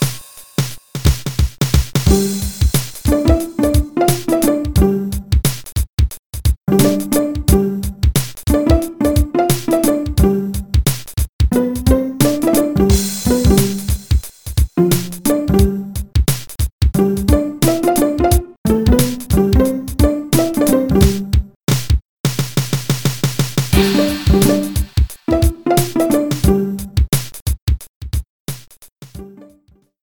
The menu theme